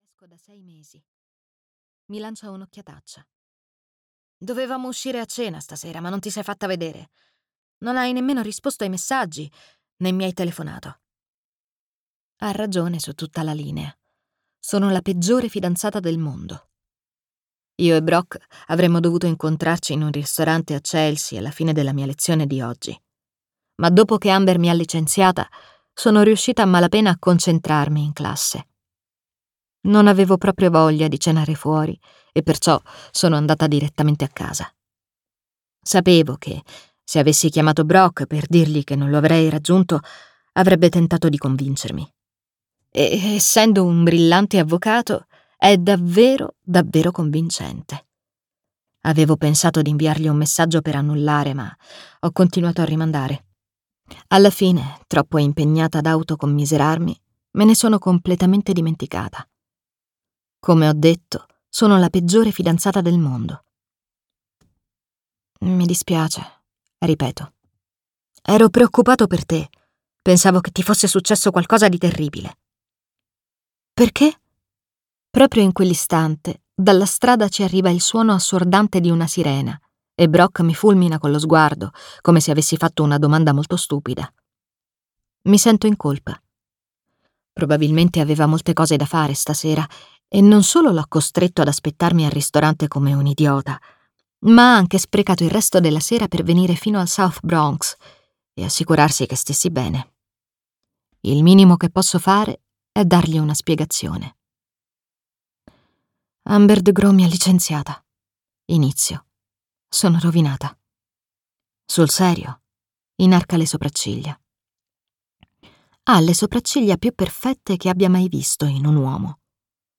"Nella casa dei segreti" di Freida McFadden - Audiolibro digitale - AUDIOLIBRI LIQUIDI - Il Libraio